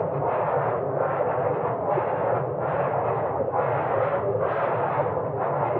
frostloop02.wav